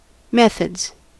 Ääntäminen
Ääntäminen US Haettu sana löytyi näillä lähdekielillä: englanti Käännöksiä ei löytynyt valitulle kohdekielelle. Methods on sanan method monikko.